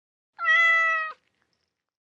meow5.mp3